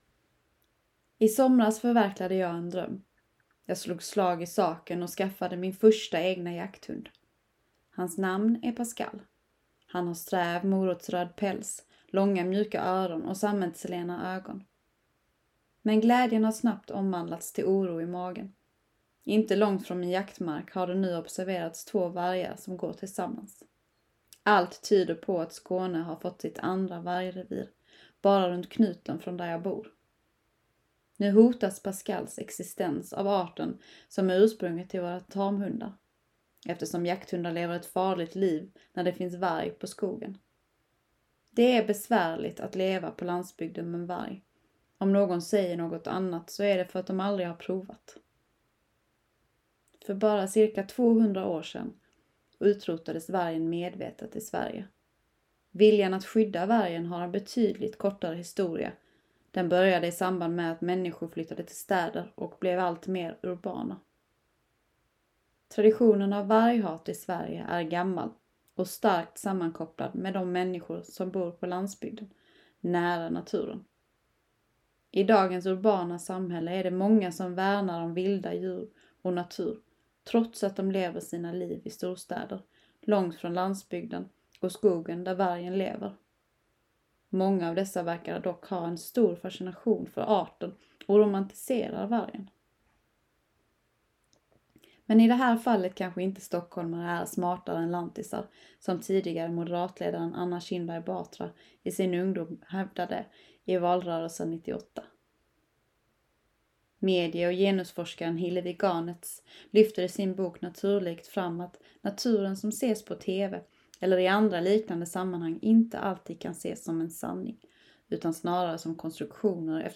Essä & ljudessä